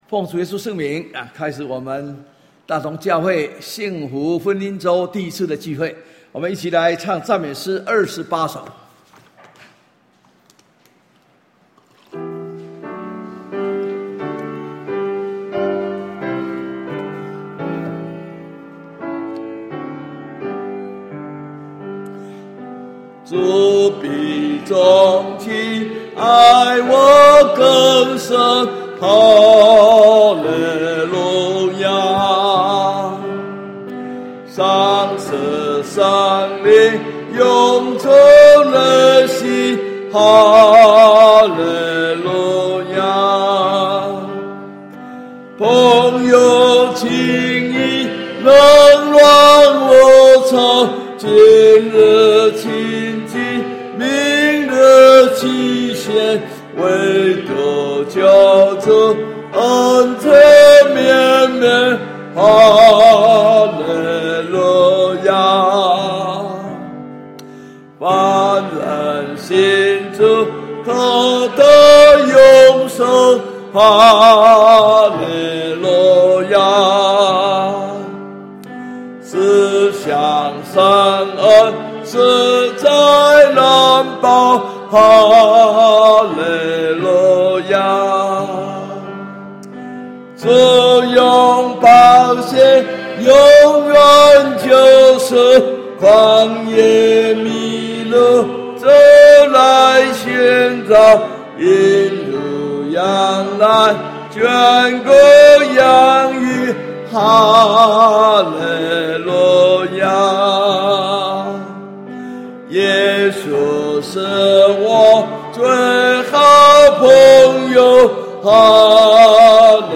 幸福婚姻週專題講道